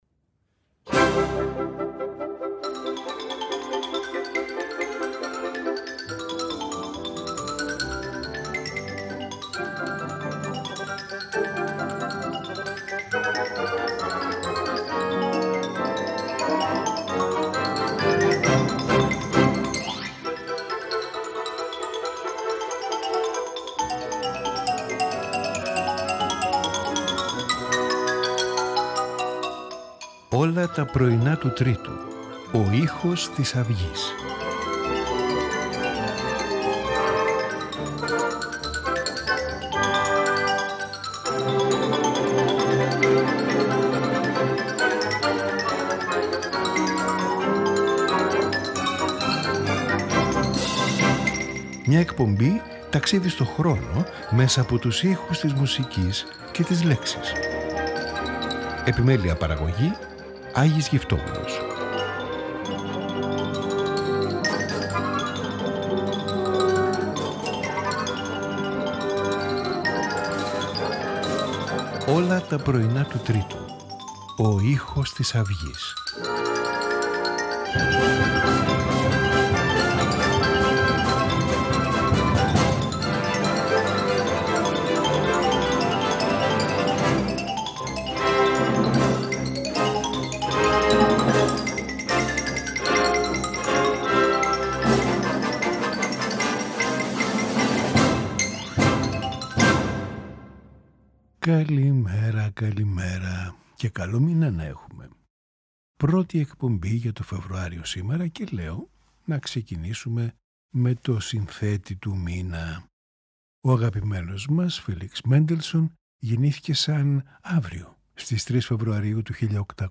Piano Concerto
Flute Concerto
Flute, Cello and Piano
String Quintet